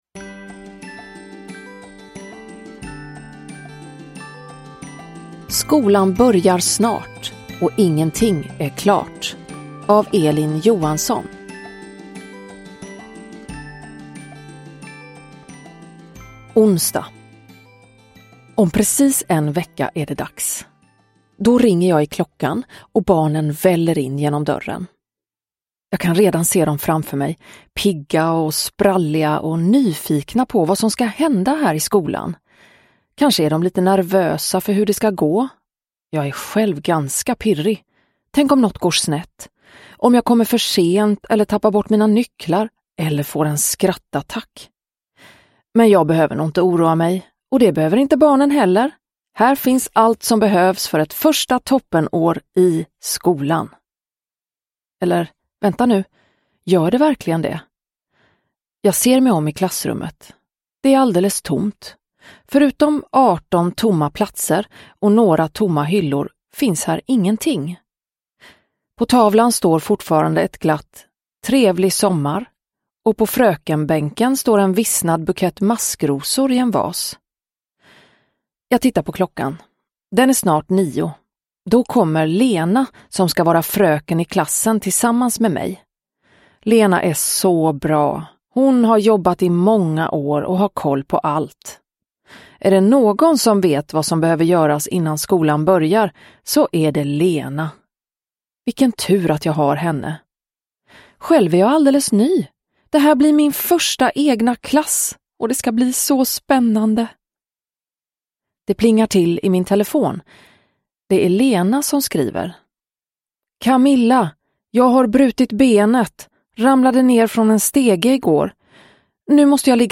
Skolan börjar snart ... och ingenting är klart! – Ljudbok – Laddas ner
Uppläsare: Klara Zimmergren